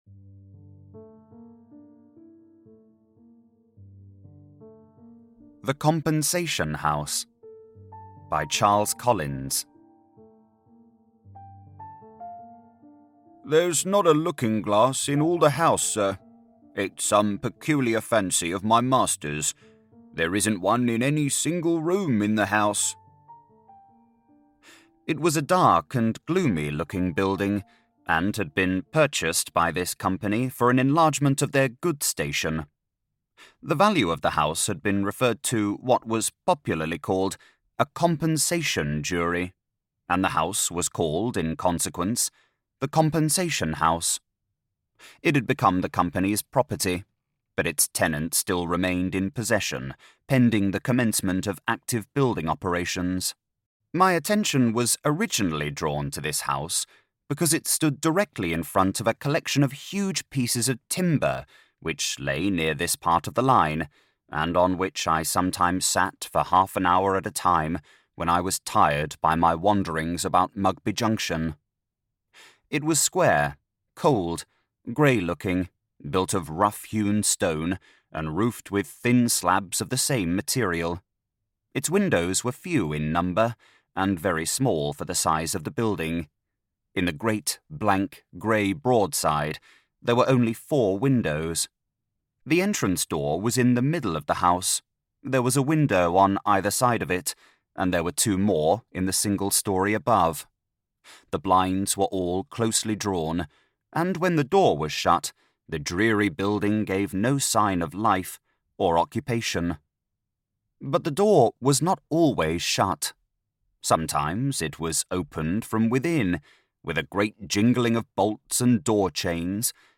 The Compensation House by Charles Collins - Dark Short Story Audiobook